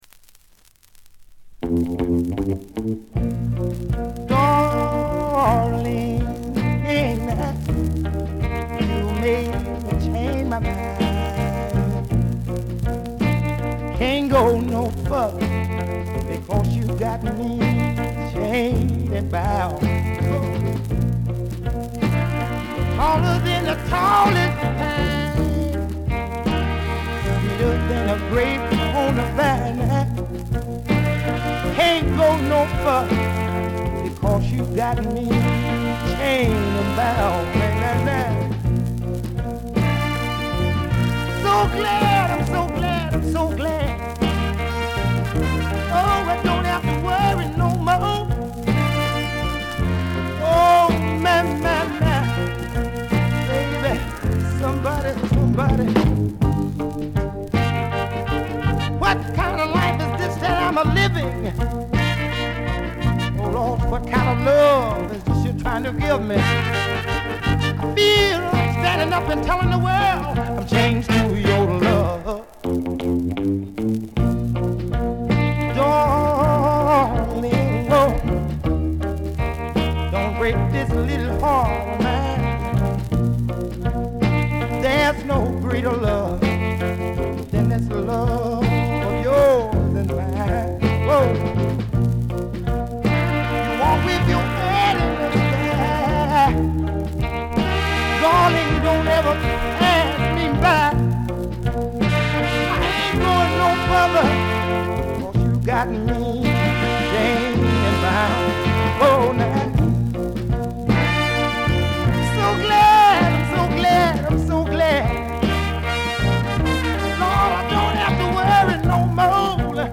バックグラウンドノイズ（A2序盤が特に目立つ）、チリプチ。
モノラル盤。
試聴曲は現品からの取り込み音源です。